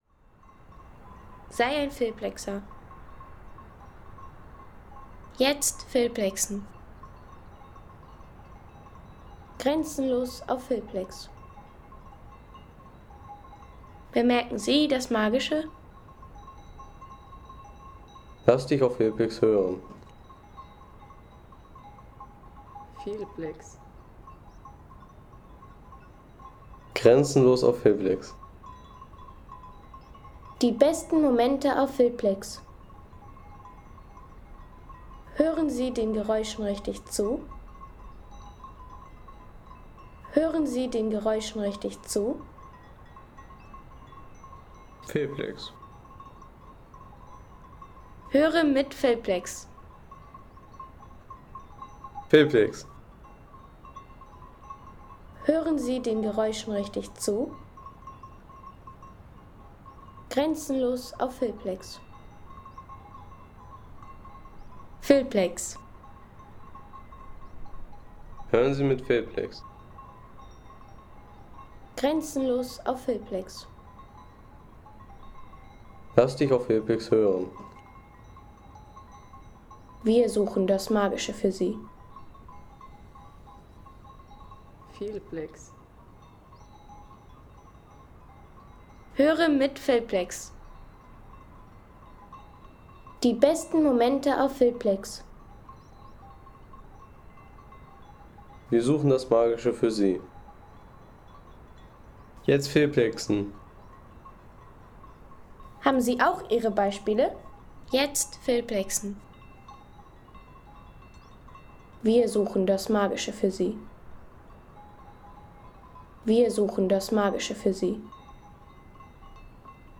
Alm Glocken Sound in den Alpen | Feelplex
Ein Glockensound irgendwo zwischen Alm und Wolken
Atmosphärischer Alm-Sound auf 2.600 Metern mit Alpenwind, Glocken und weidenden Schafen.
Natürliche Alpenatmosphäre mit sanftem Wind, Glockenläuten und weidenden Schafen von einer hochgelegenen Alm.